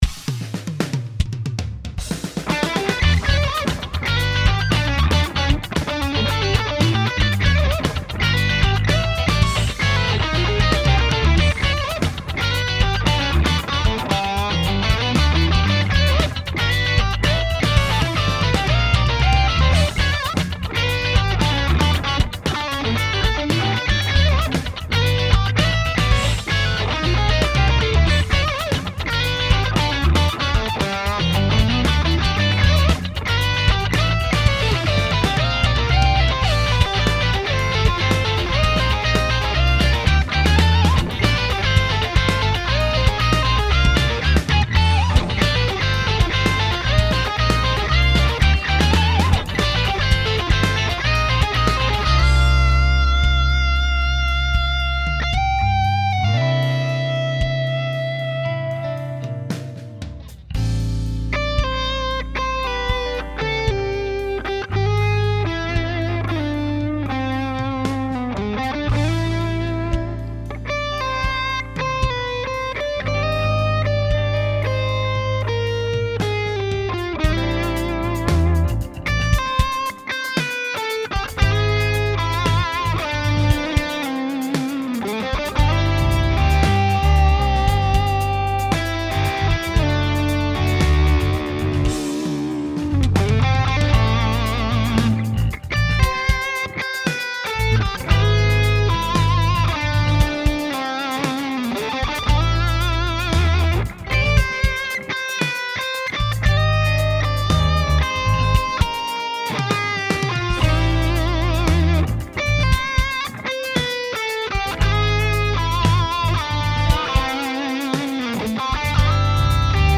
Genre: Fusion.